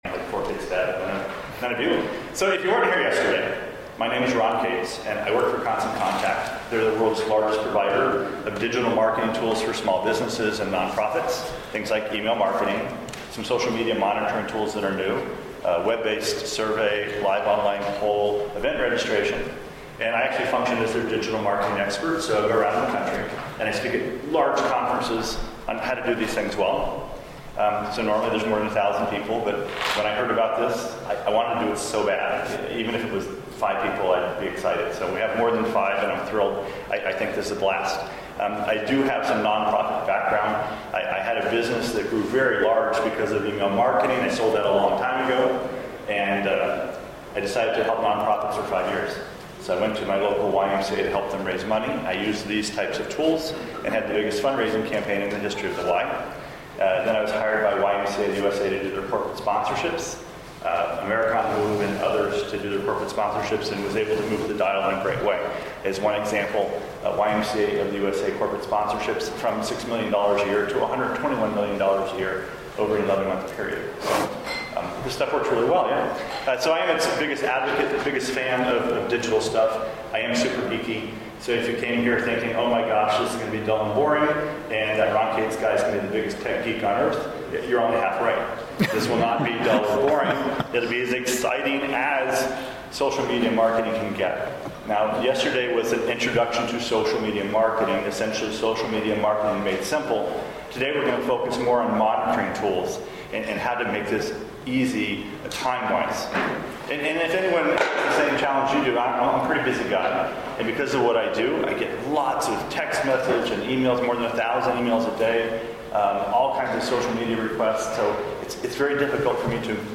Presented at RA Convention 2011